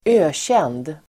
Uttal: [²'ö:tjen:d]